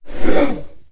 battlemage_attack7.wav